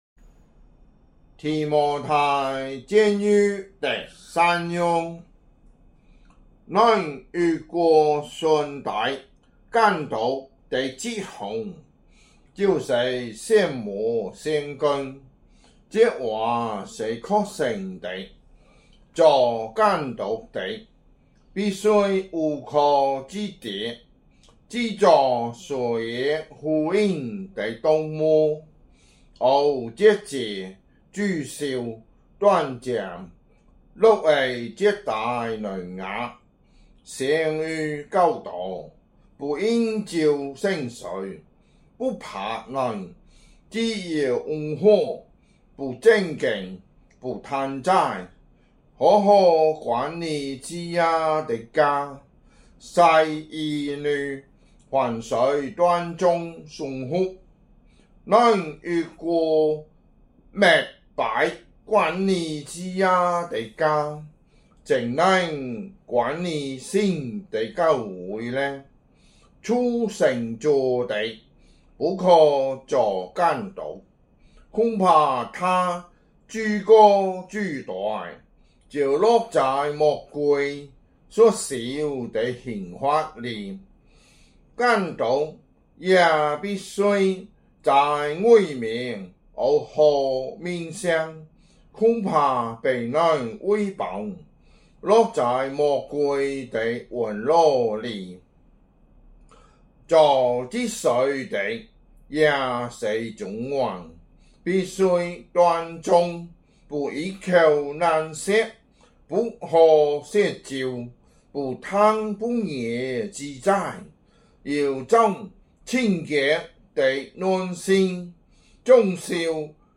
福州話有聲聖經 提摩太前書 3章